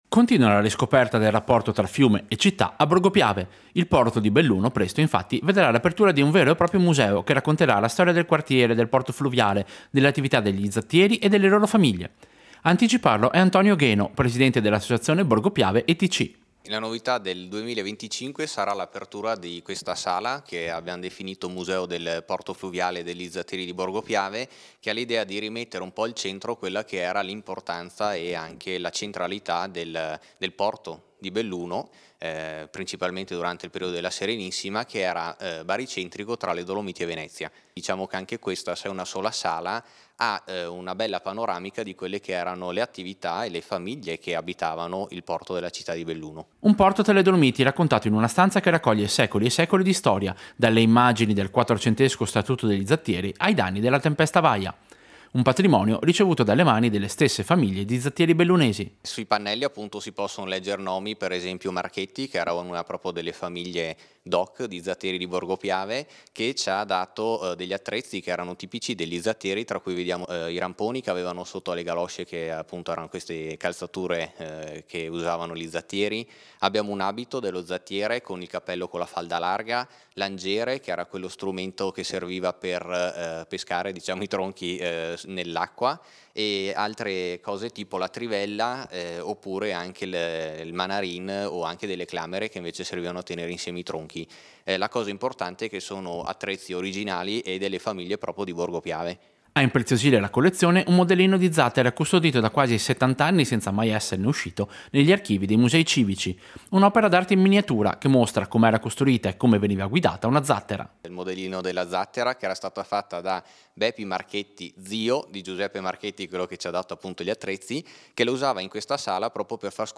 Servizio-Museo-Porto-fluviale-Belluno.mp3